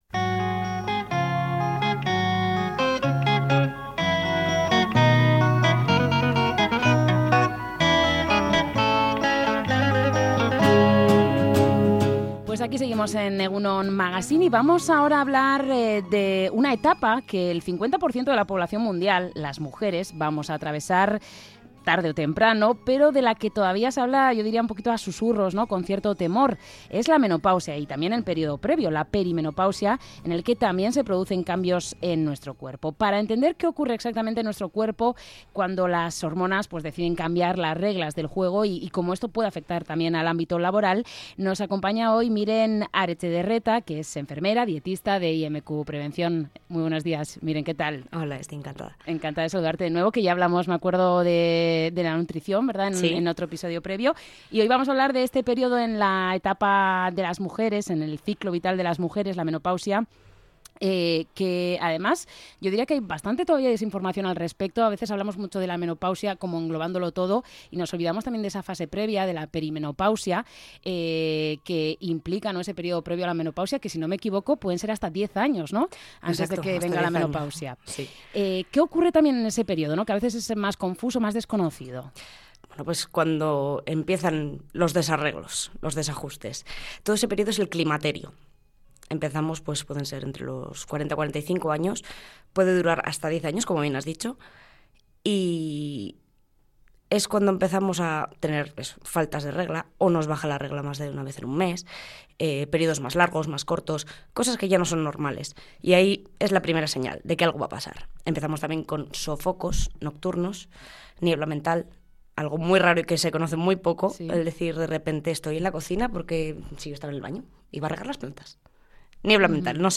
ENTREV.-MENOPAUSIA-IMQ.mp3